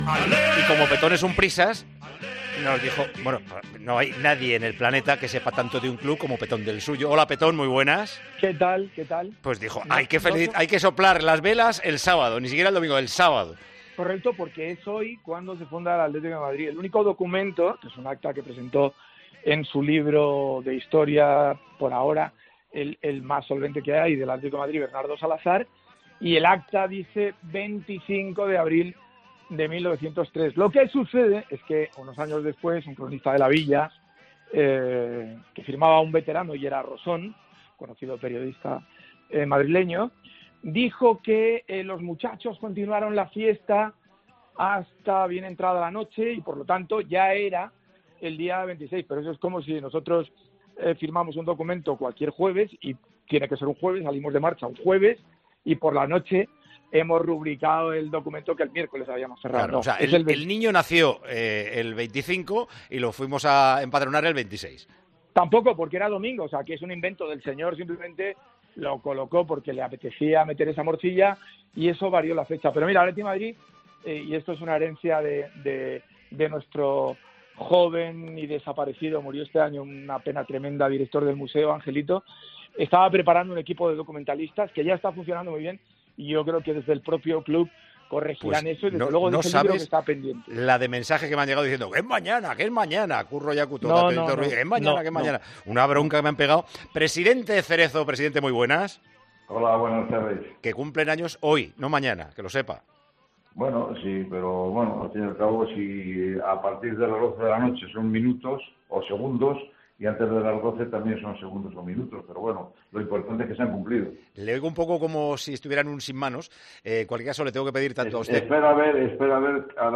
Tiempo de Juego llama al presidente del Atlético de Madrid y a Petón en vísperas del 117 cumpleaños del club rojiblanco.